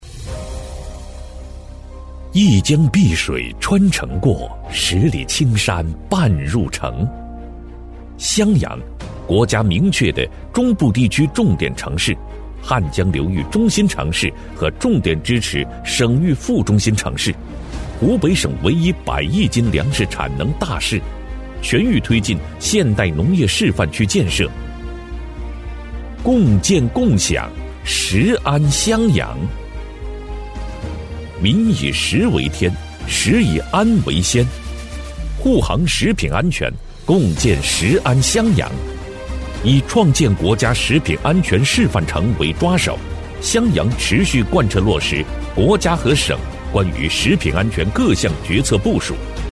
男71-城市专题《共建共享 食安襄阳》-硬朗力度
男71-城市专题《共建共享  食安襄阳》-硬朗力度.mp3